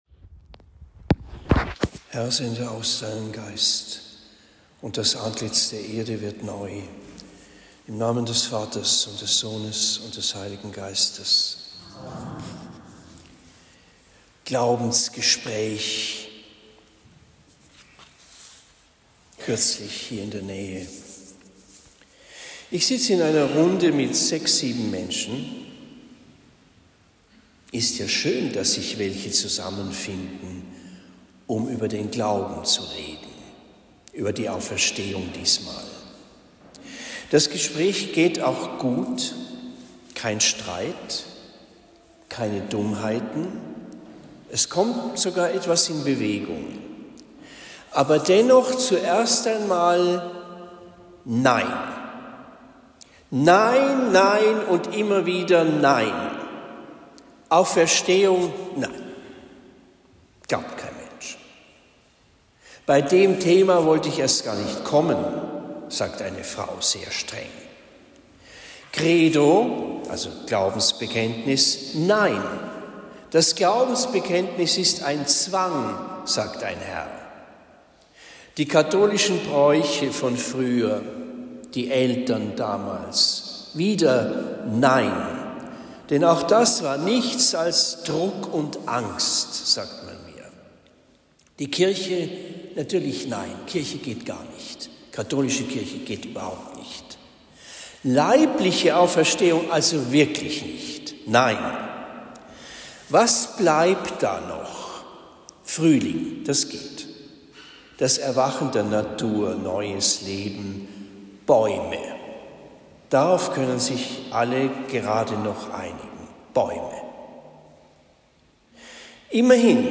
Vierter Sonntag der Osterzeit 2024 Predigt am 21. April 2024 in Homburg St.-Burkhard